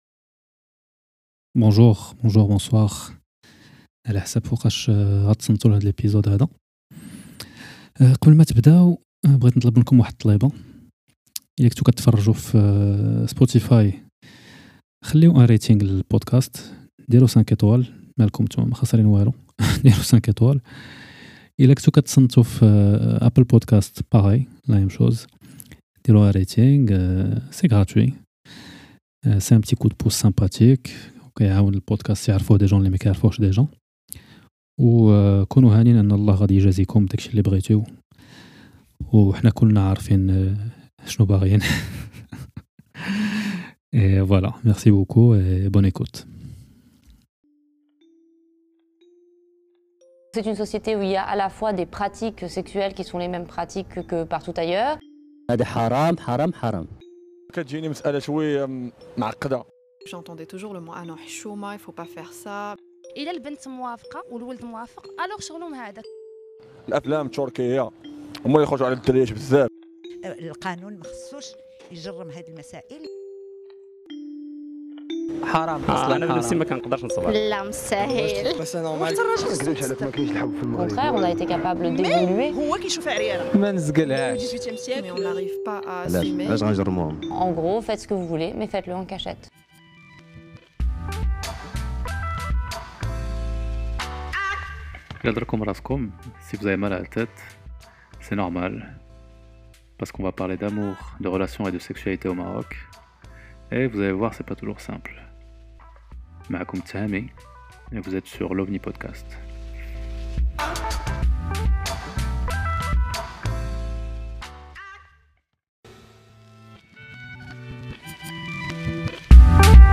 Je m’excuse pour la qualité du son dans certains de ces Lives, étant donné que l’enregistrement se fait sur téléphone (contrairement aux épisodes).